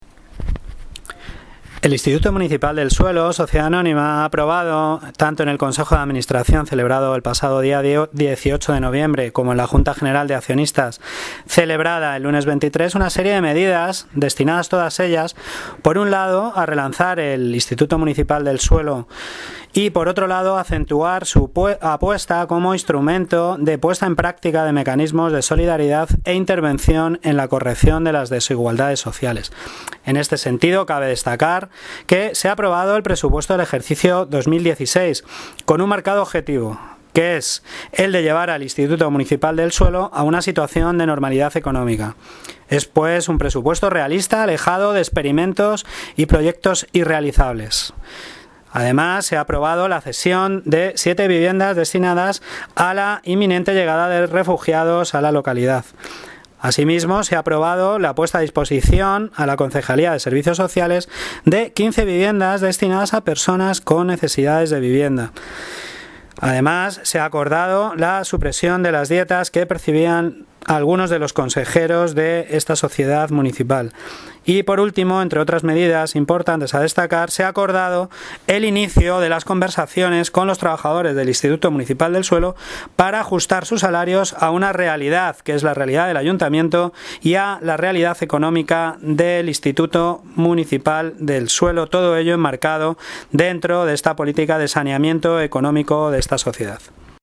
Audio - Eduardo Gutierrez (Consejal Urbanismo y Vivienda) sobre IMS